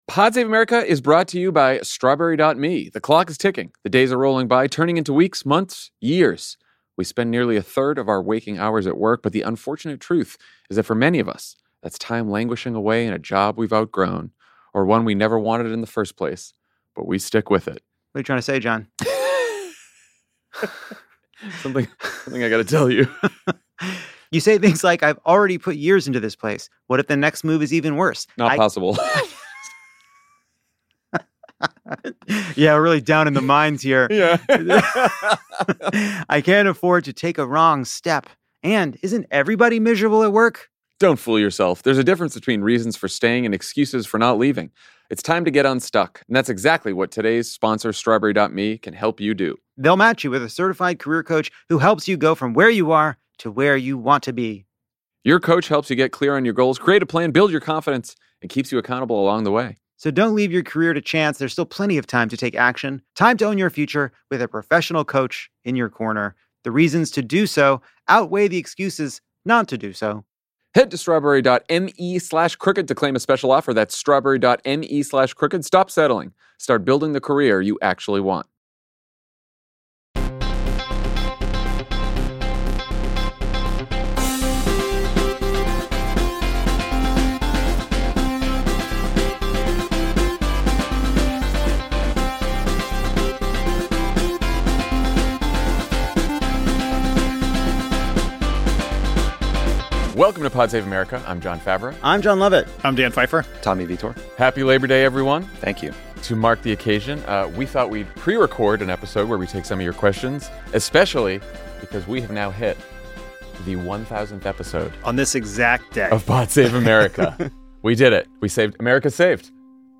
Pod Save America hits 1,000 episodes, and to celebrate, Favreau, Lovett, Tommy, and Dan sit down in studio to answer your questions. Among them: Why is JD Vance so grating? Should more Democrats take Newsom's lead on social media?